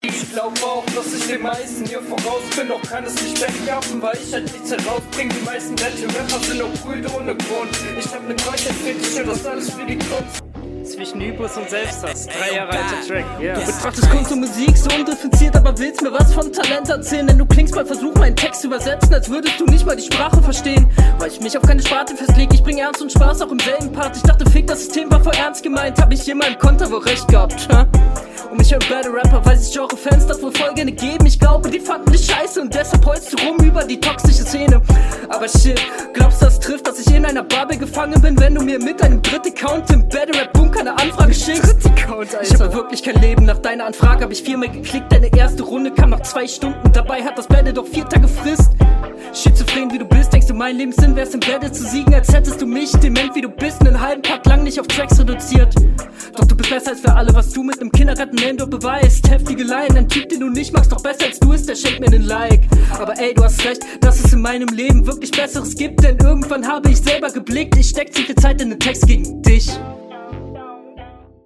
Rap ist stabil, aber hält nicht mit der HR mit.